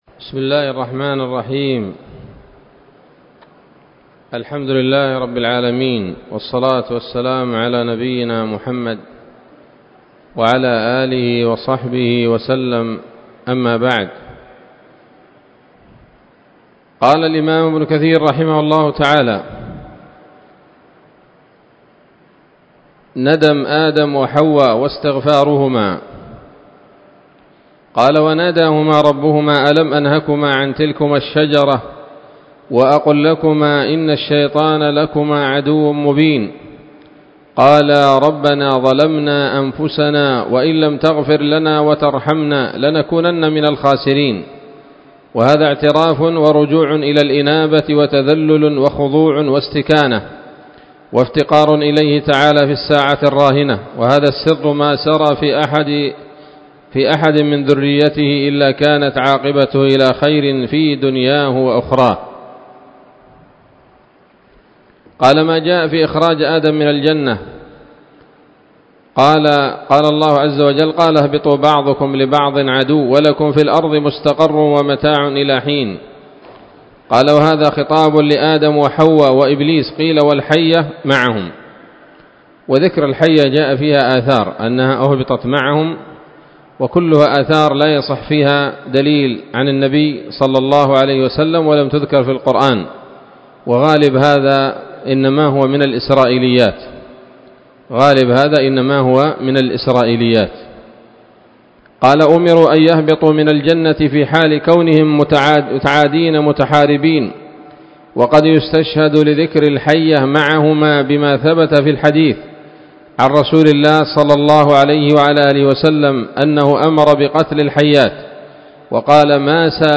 الدرس السابع من قصص الأنبياء لابن كثير رحمه الله تعالى